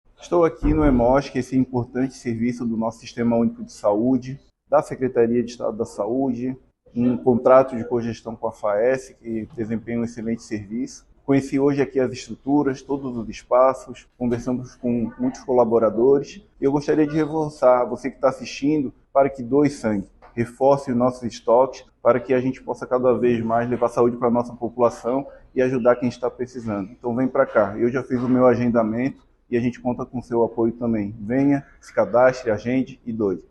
Nesta quinta, 22, o secretário de Estado da Saúde, Diogo Demarchi, esteve no Hemosc de Florianópolis para tratar de demandas da unidade e reforçou a importância da doação de sangue para salvar vidas:
SECOM-Sonora-Secretario-Saude-Hemosc-Doacao-Sangue.mp3